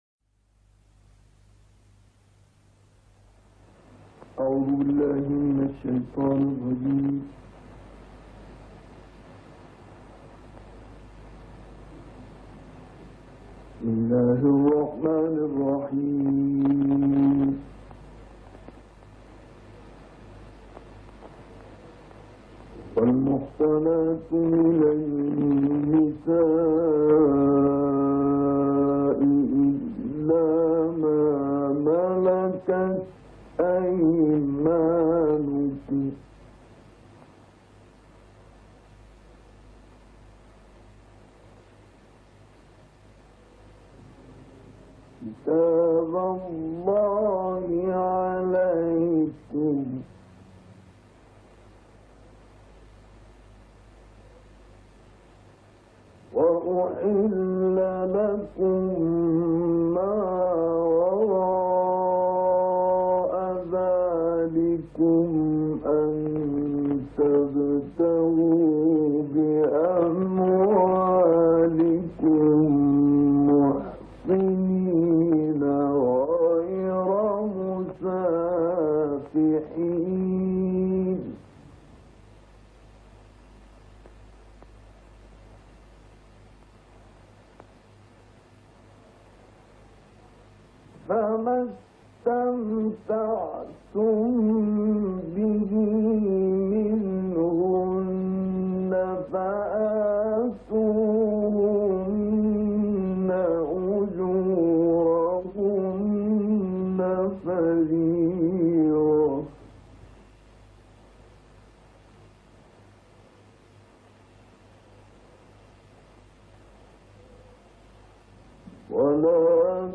تلاوت استاد مصطفی اسماعیل از آیات 24 الی 36 سوره نساء را که در استودیوی رادیو بغداد ضبط شده است را در ادامه بشنوید.